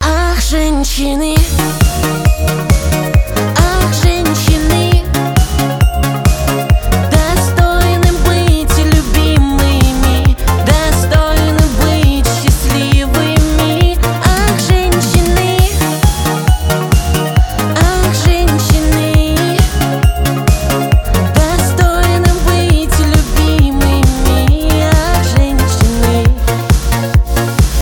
поп
душевные